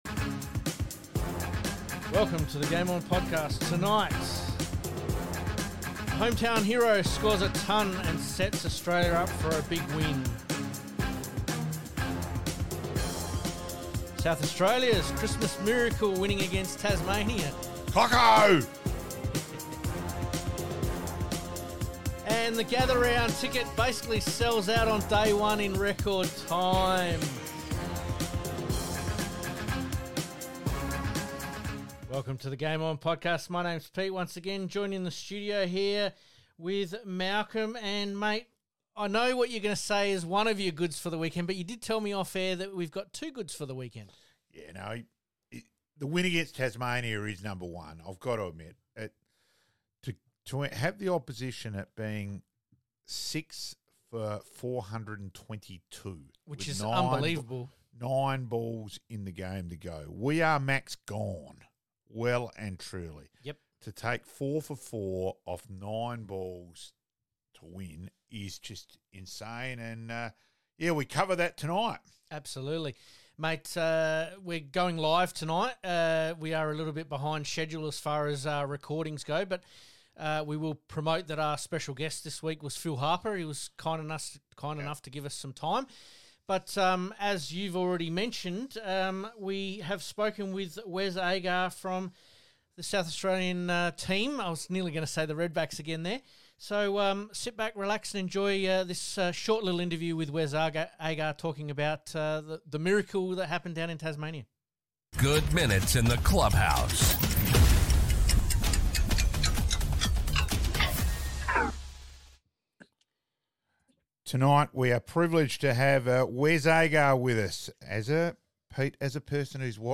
🔥 THIS WEEKS PODCAST is Available NOW & WAS LIVE🔥